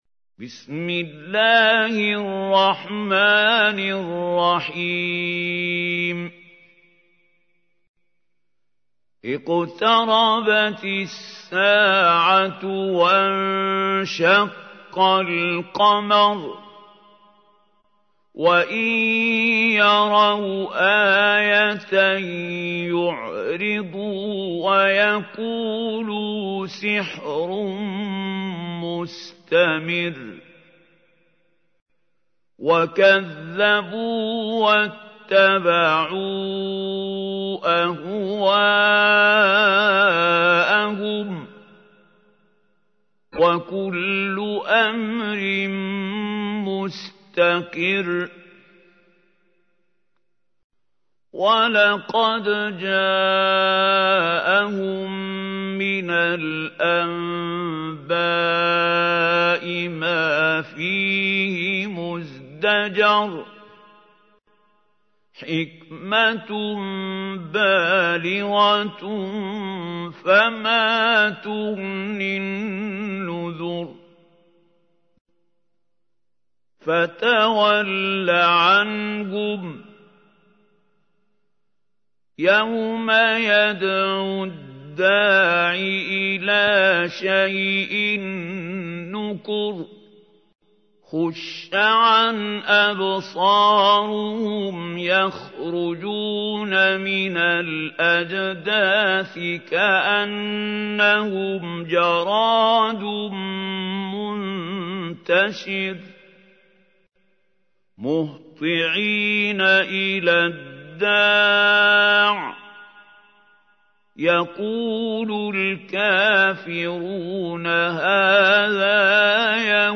تحميل : 54. سورة القمر / القارئ محمود خليل الحصري / القرآن الكريم / موقع يا حسين